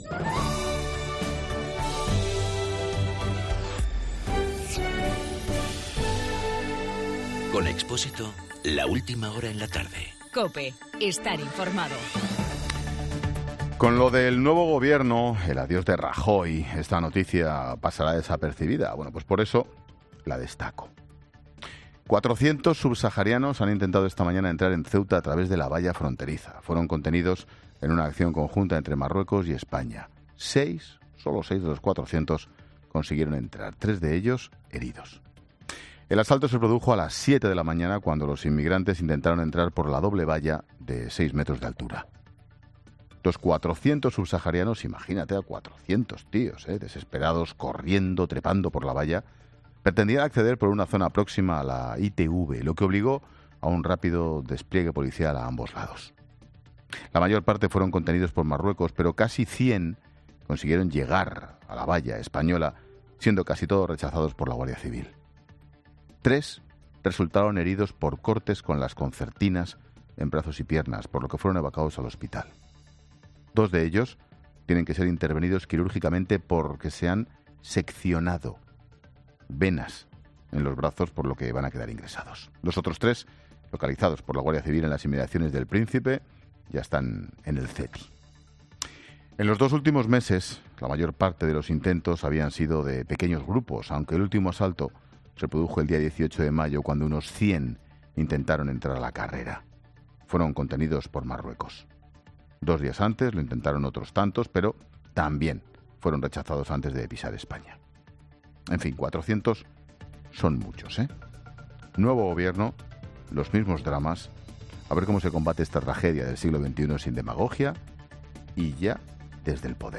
Monólogo de Expósito
El comentario de Ángel Expósito sobre el intento de 400 subsaharianos de pasar la valla de Ceuta.